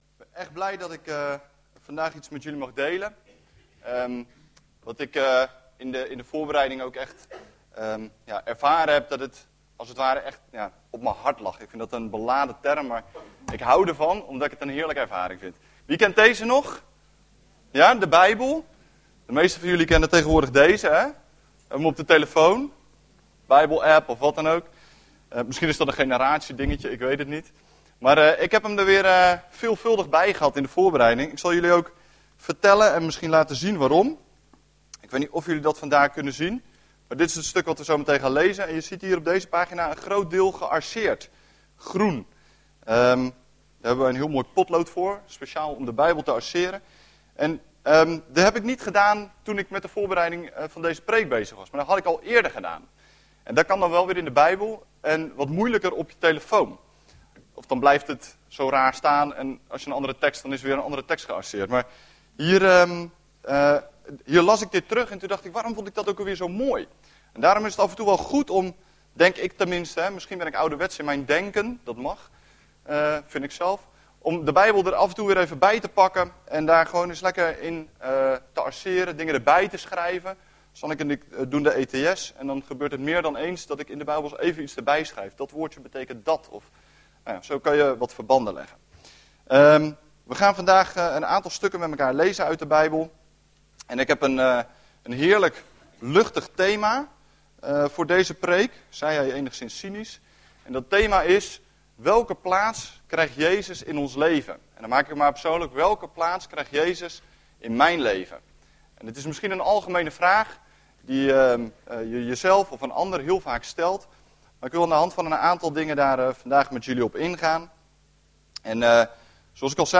Overzicht van preken van Preken op Christengemeente Bethel